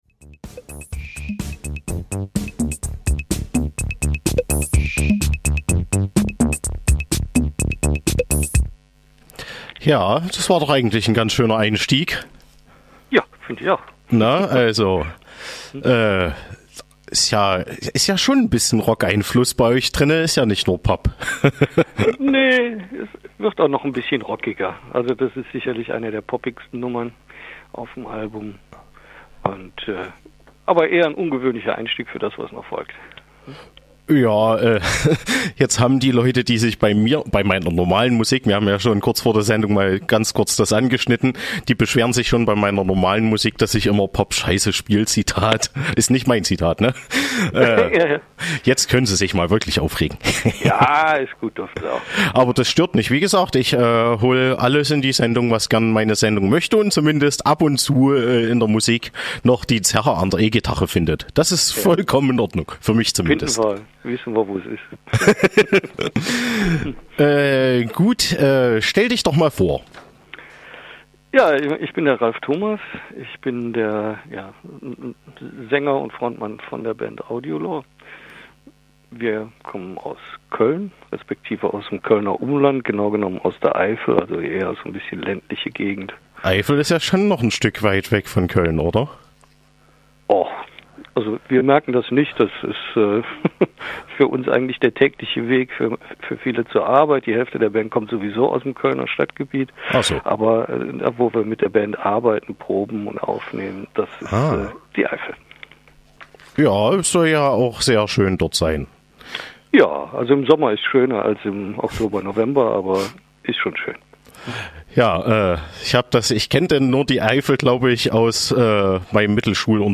Radio-Mitschnitt vom 18.10.2020:Coloradio
Interview